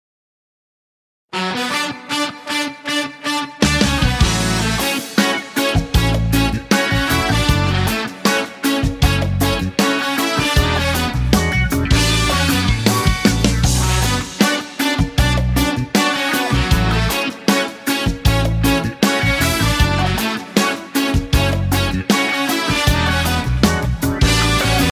• Качество: 320, Stereo
без слов
Cover
инструментальные
трубы